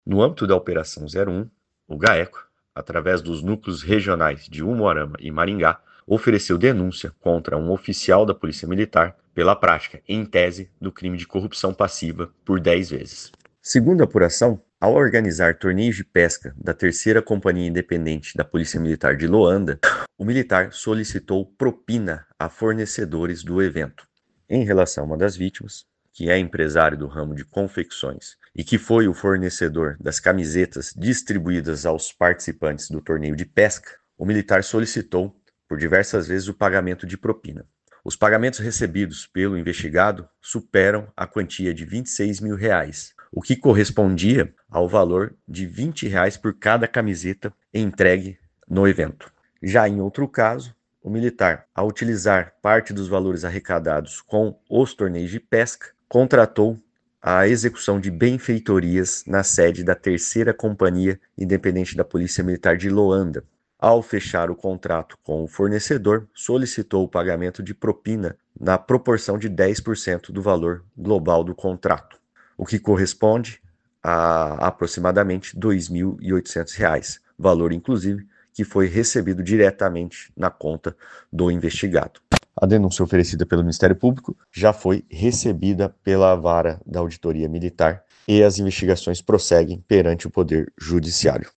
Ouça o que diz o promotor Guilherme Franchi da Silva Santos: